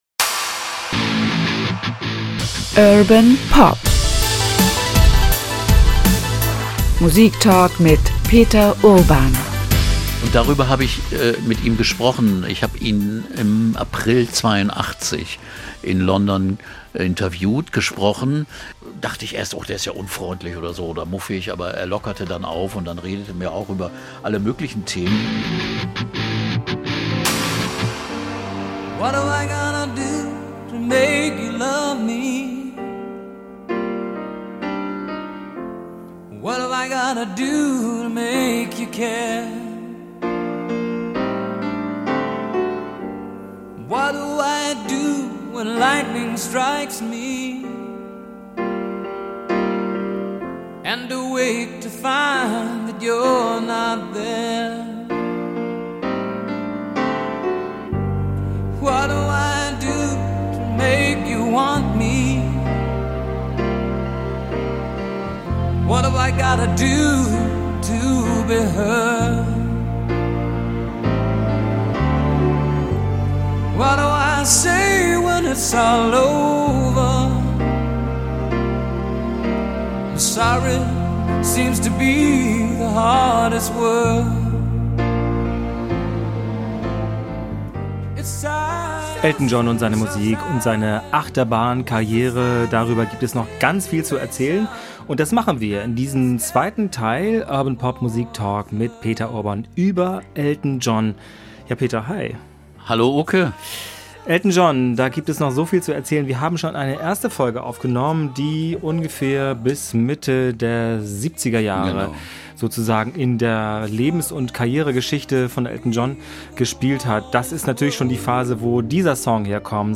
Peter Urban schätzt das musikalische Schaffen von Elton John ein.